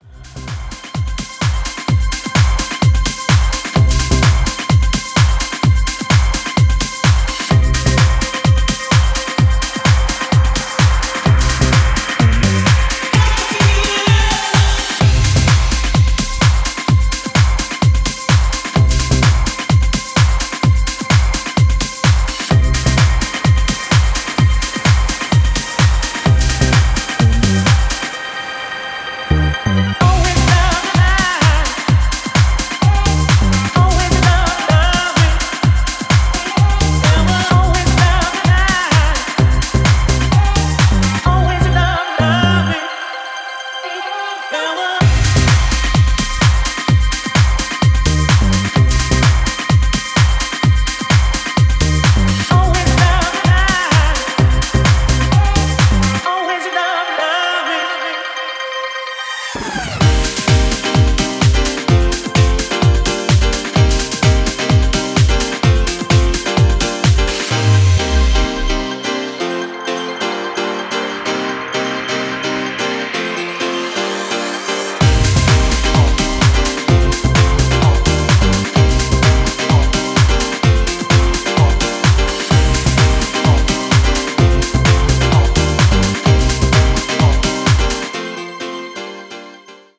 UKレイヴとUSハウスの折衷を試みるような、ピークタイム仕様の意欲作です。